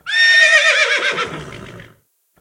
PixelPerfectionCE/assets/minecraft/sounds/mob/horse/angry1.ogg at 2a030be26a7098a0179ec20434e26d006b4eb72f
angry1.ogg